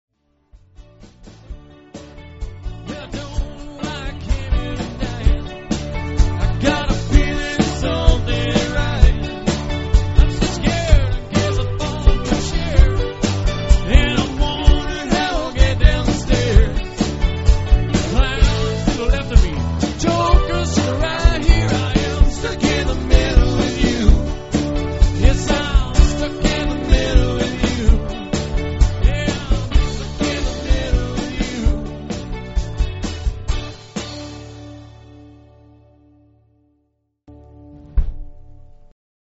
Rock & Country, New and Old